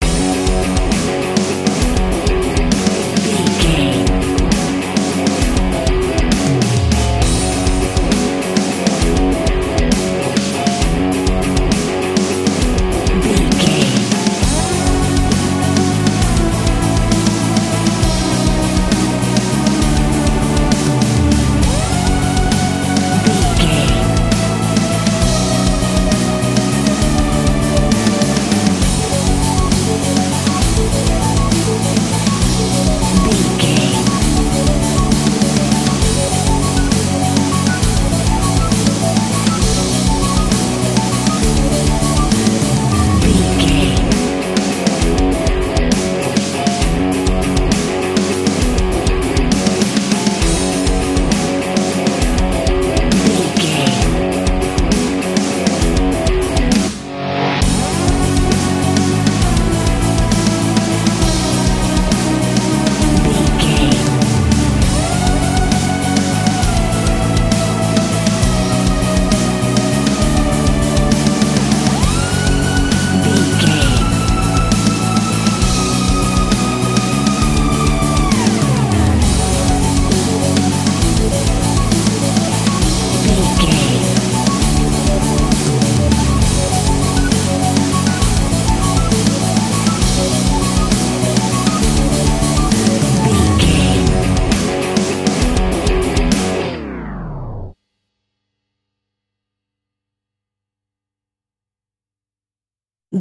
Aeolian/Minor
F#
drums
electric guitar
bass guitar
hard rock
metal
aggressive
energetic
intense
nu metal
alternative metal